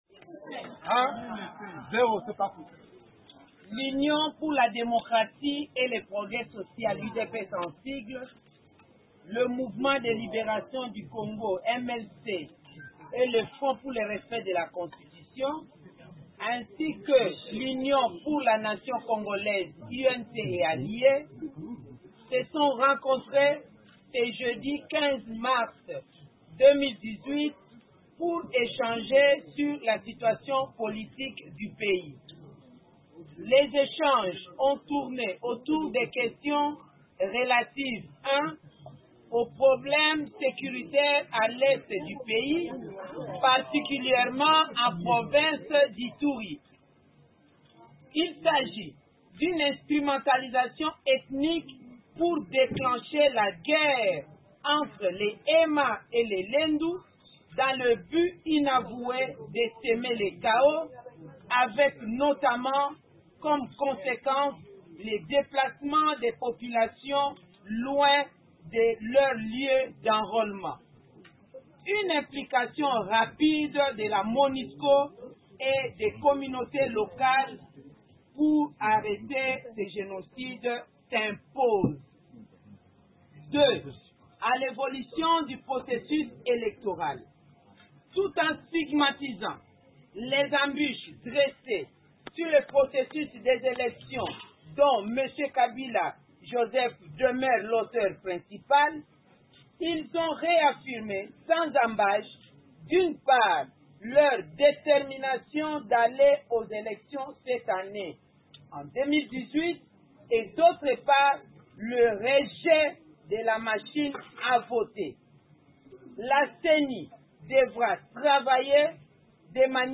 Déclaration de l’UDPS, de l’UNC et du MCL lue par Mme Bazaiba